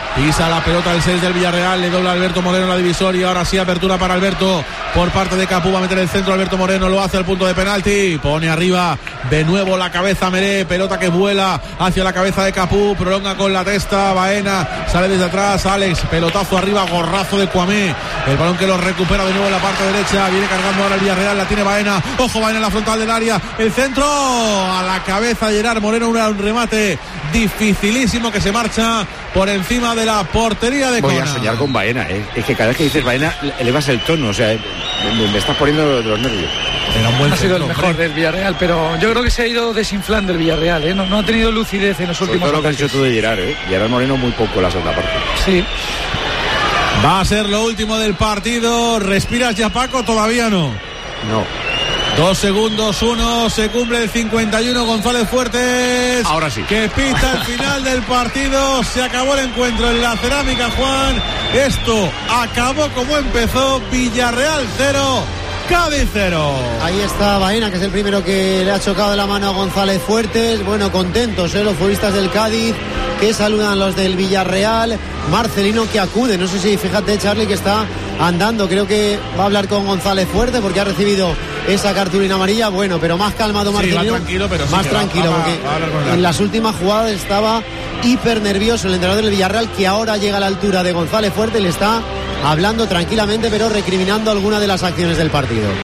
Así contamos en Tiempo de Juego el final de Villarreal 0 - Cádiz 0